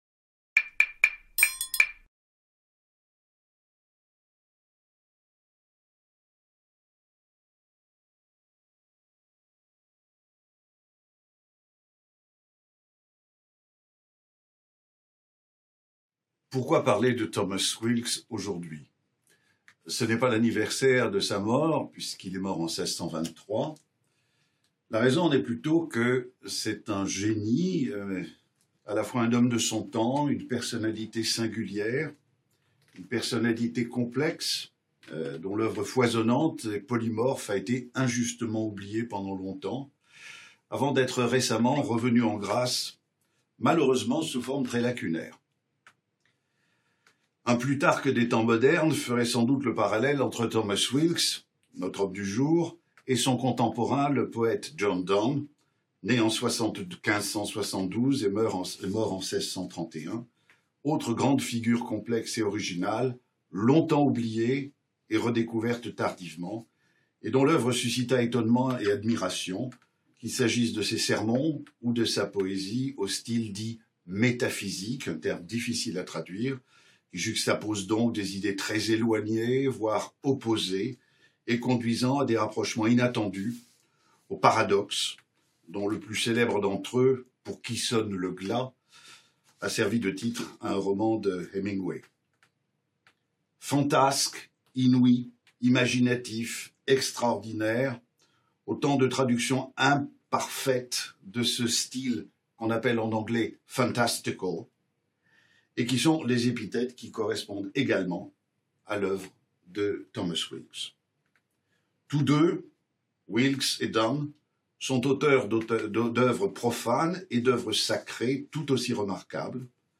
Concert Sorbonne Scholars — Les audaces de Thomas Weelkes (1576-1623) | Canal U
S'appuyant sur des extraits du concert donné en novembre 2021 par les Sorbonne Scholars